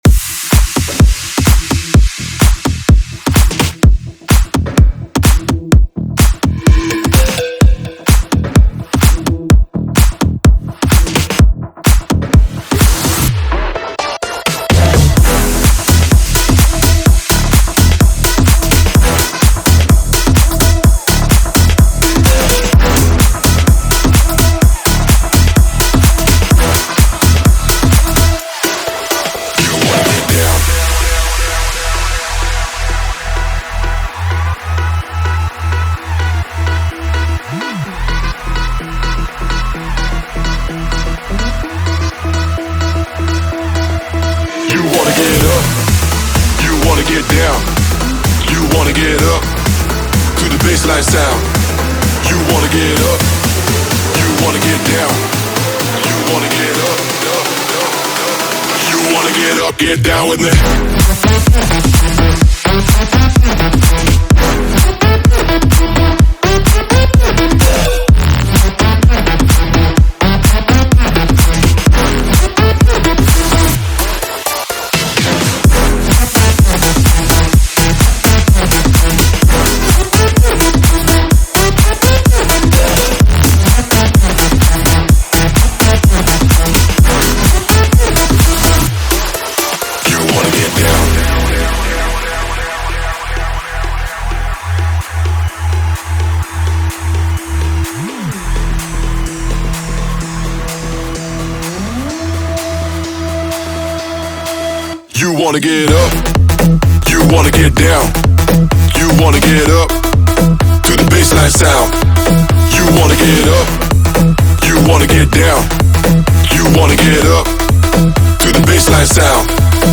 这些和弦具有更动人的音调。
该项目文件是您在此包的预告片视频中听到的歌曲的源项目。